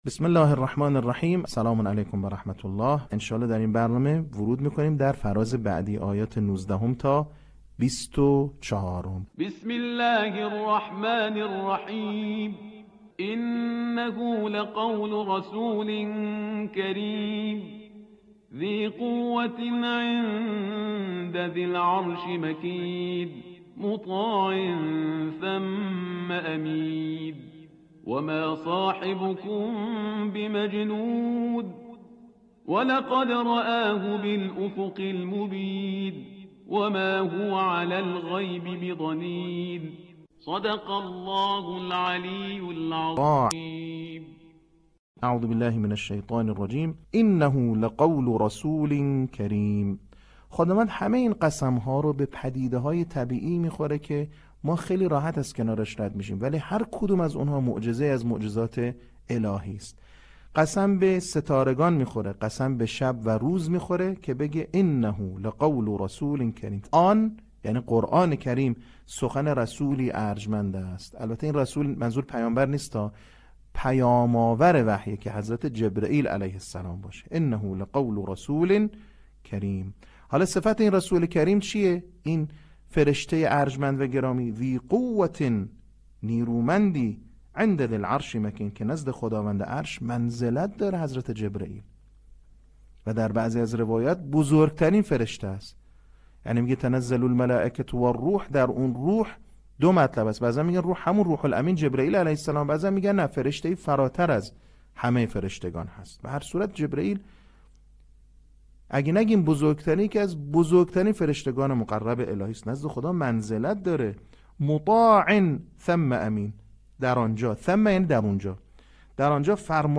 صوت | بخش چهارم آموزش حفظ سوره تکویر